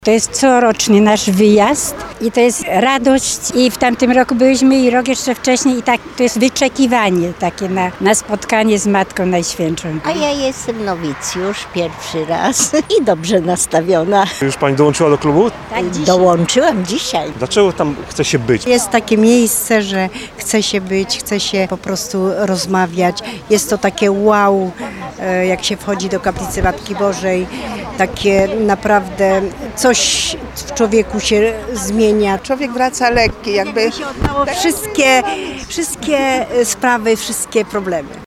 Słuchacze, w tym członkowie radiowego Klubu Dobrze Nastawionych nie ukrywali swojej radości ze wspólnego pielgrzymowania.